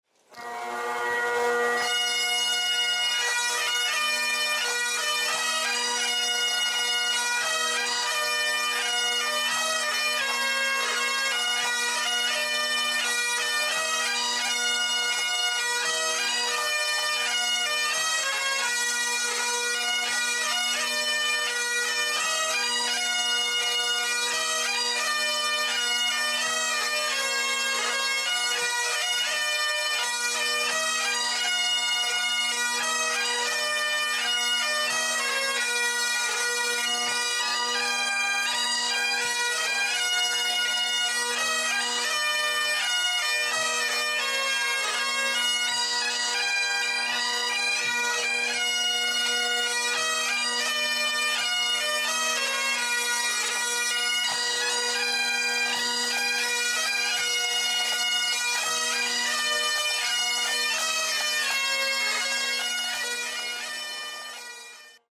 Pipes and Drums… schottisches Brauchtum durfte im letzten Oktober auch an unserer Ladeneröffnung / Pick-up in Kreuzlingen nicht fehlen. Die Formation aus Basel hat unsere zahlreichen Gäste vortrefflich unterhalten.
Pipes and Drums aus Basel
pipes-and-drums-basel.mp3